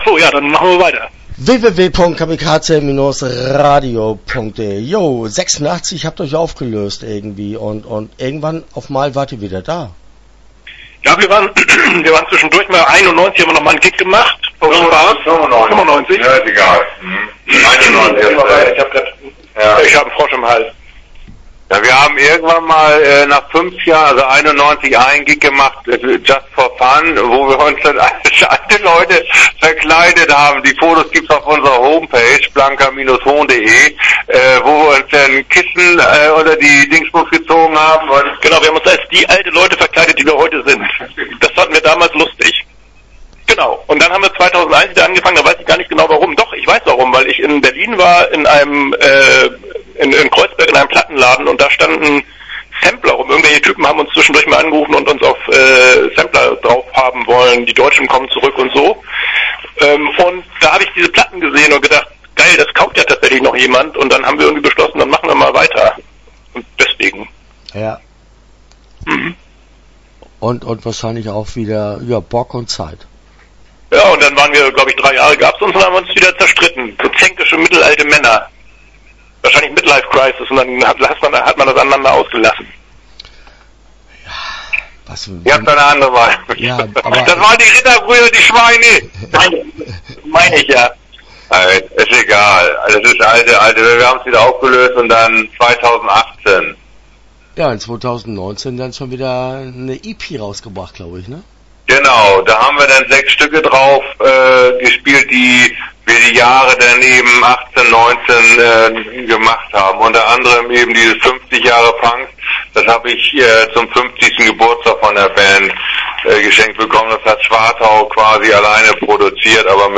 Blanker Hohn - Interview Teil 1 (12:43)